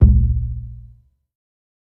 KICK_WORM.wav